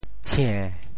潮州发音